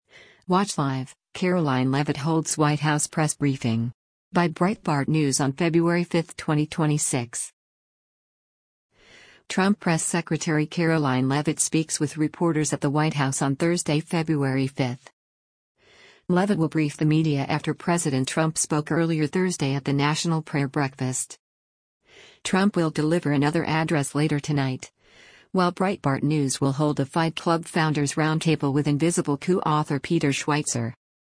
Trump Press Secretary Karoline Leavitt speaks with reporters at the White House on Thursday, February 5.